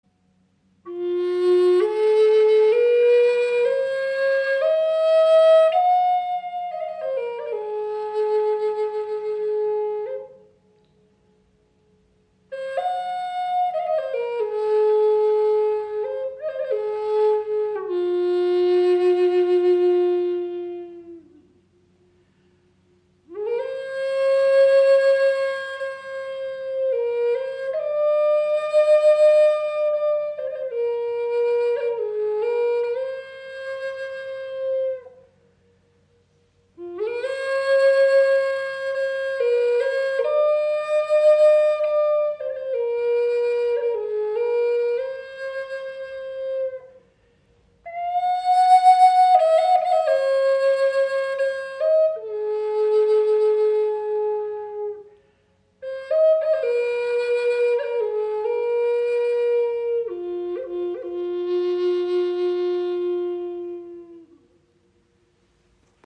Gebetsflöte in F
Wundervolle Flöte aus brasilianischem Imbuiaholz
• Icon 54 cm lang, 6 Grifflöcher
Sie schenkt Dir ein wundervolles Fibrato, kann als Soloinstrument gespielt werden oder als weiche Untermahlung Deiner Musik.